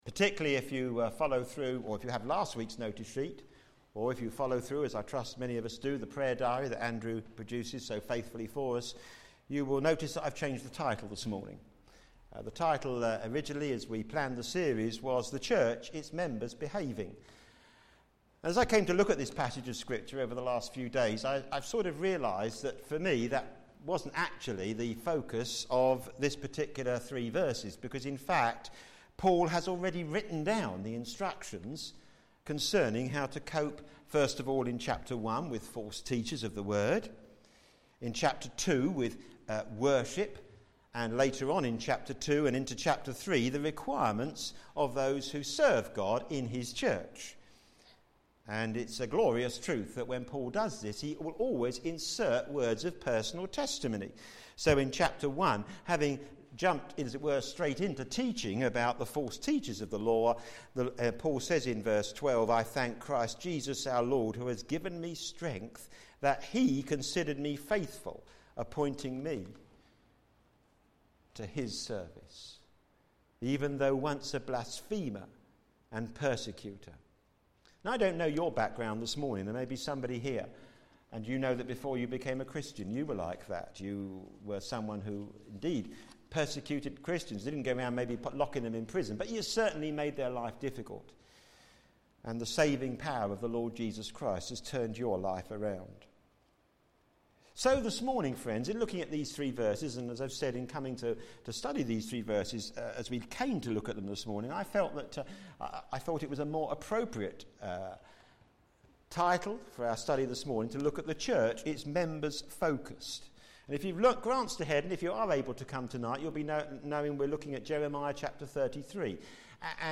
Media for a.m. Service on Sun 19th Aug 2012 10:30
The Church - The Members Focused Sermon